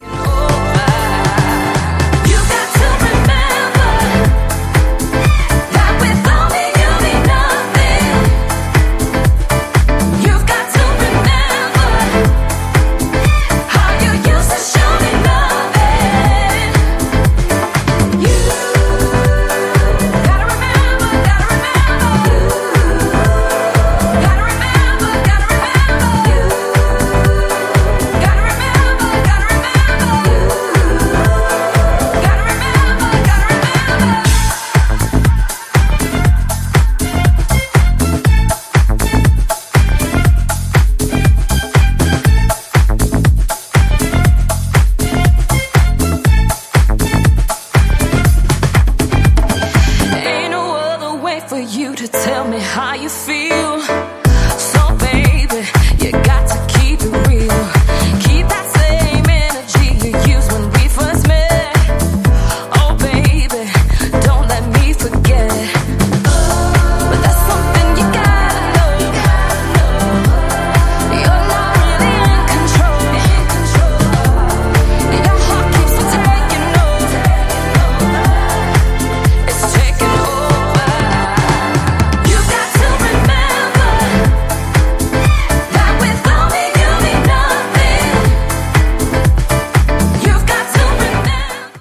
女性シンガーをフィーチャーしたエモーショナルでソウルフルな王道ヴォーカル・ハウスを展開！
ジャンル(スタイル) DISCO HOUSE / SOULFUL HOUSE